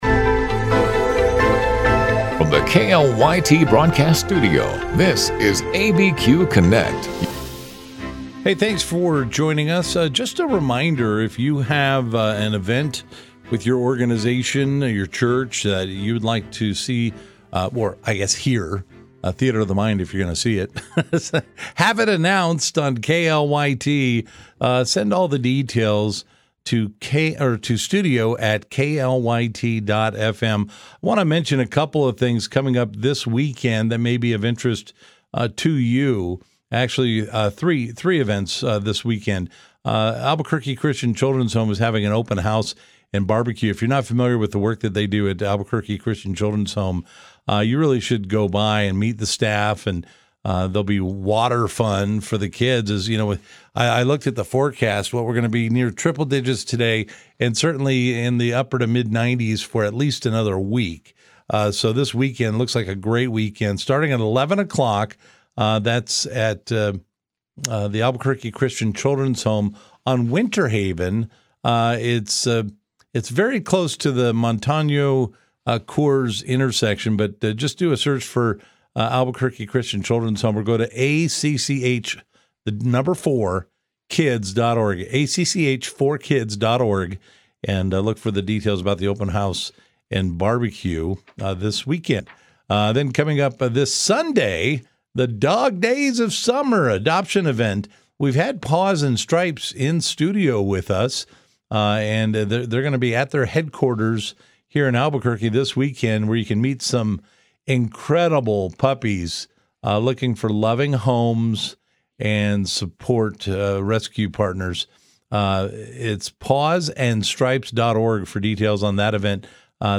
Live, local and focused on issues that affect those in the New Mexico area.